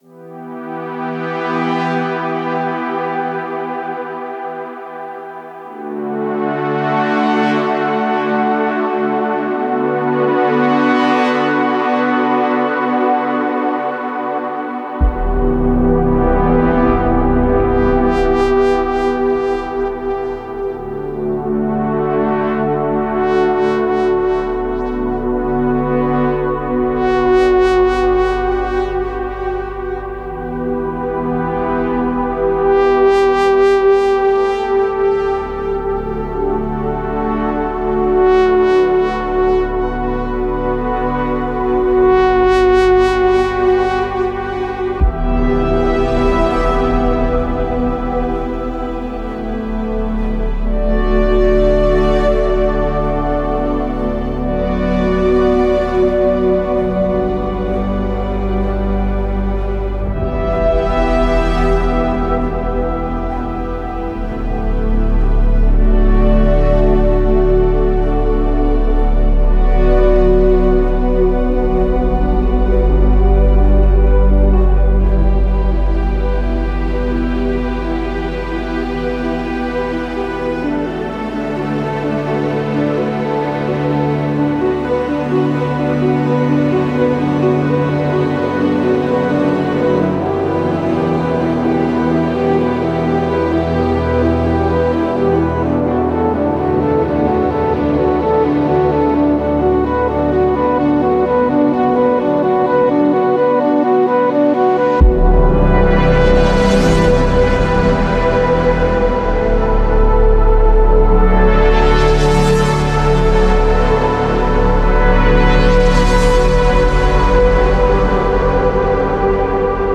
orchesta, synths
Tempo: 140 BPM (4/4) Key: C major